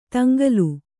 ♪ taŋgalu